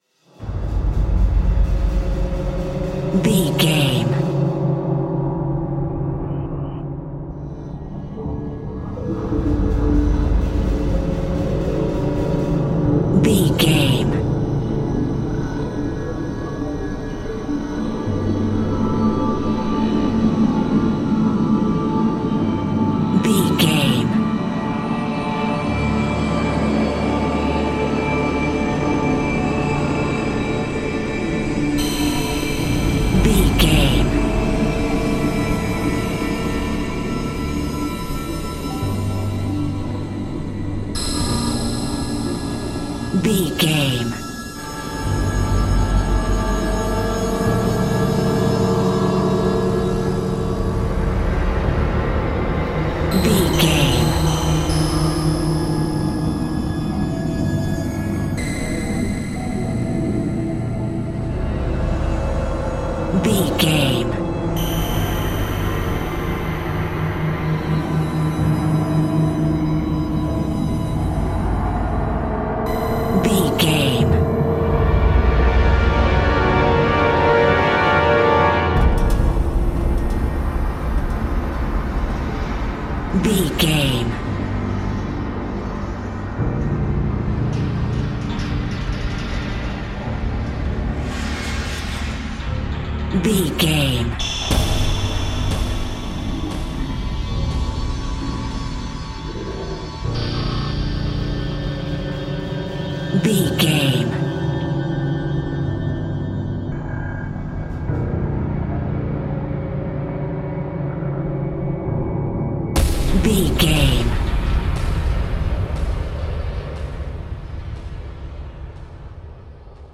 Aeolian/Minor
synthesiser
percussion